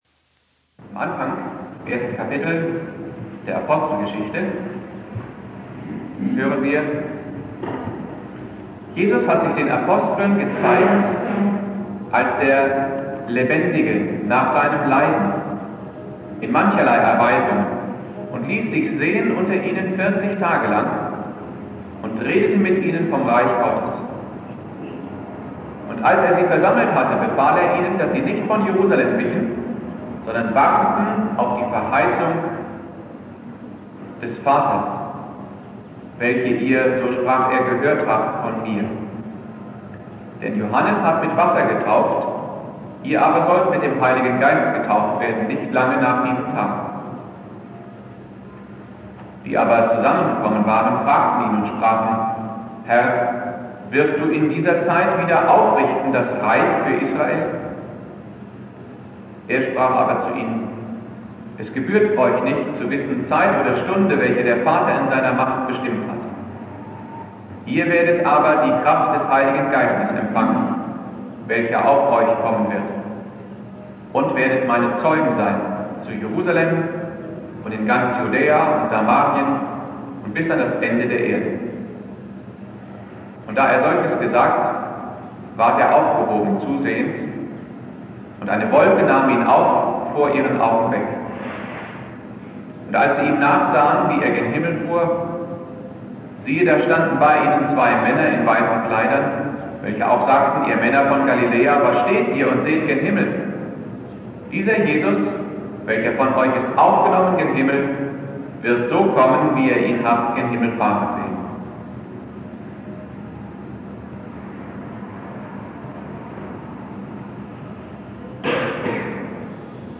Predigt
Christi Himmelfahrt
(mit Außenmikro aufgenommen)